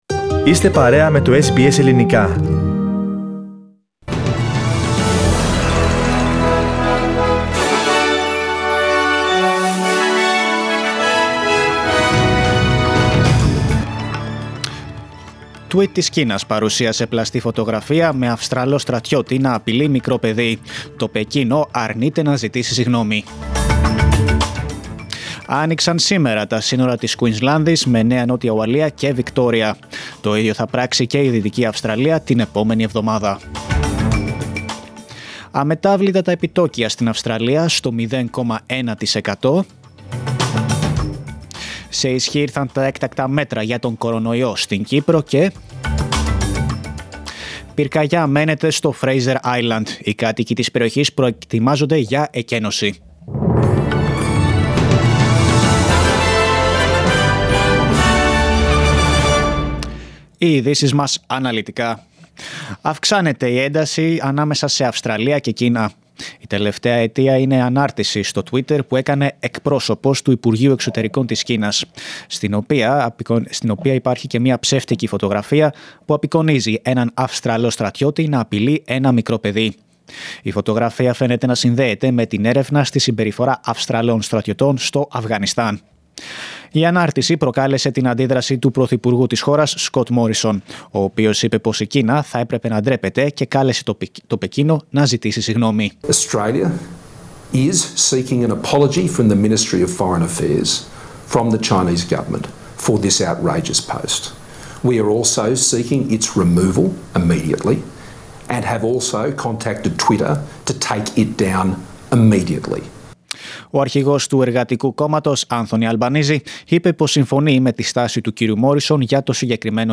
News in Greek 1st of December